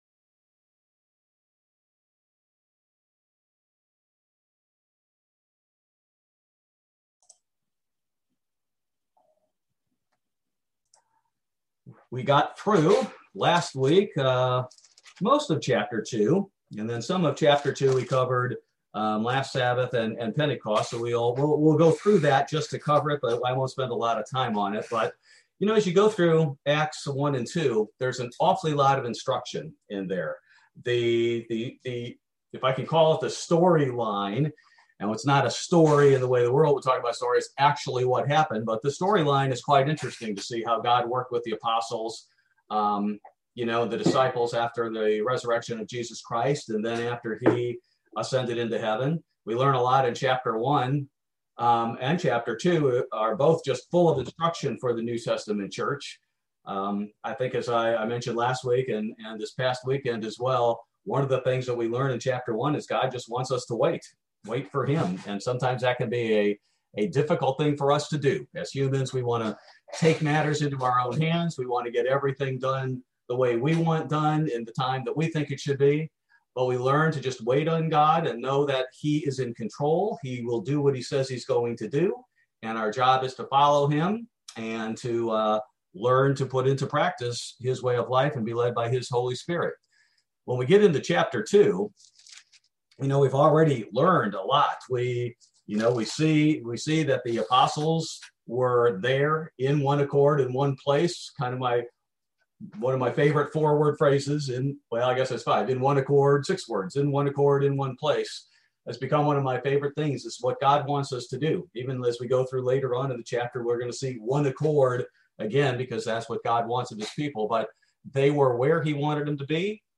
Bible Study: May 19, 2021